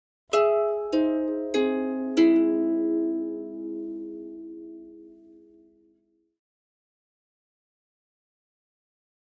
Harp, Descending Arpeggio In Two Voices, Type 2